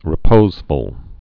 (rĭ-pōzfəl)